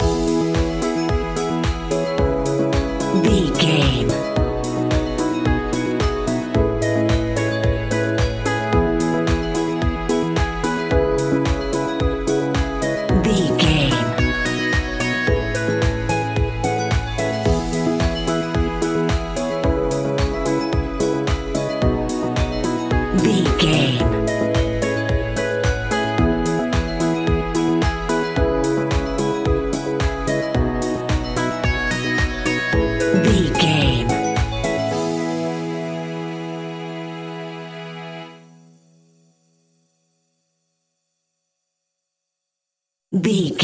Ionian/Major
peaceful
calm
joyful
hopeful
inspirational
electric piano
synthesiser
drums
strings
electro house
funky house
instrumentals
drum machine
synth bass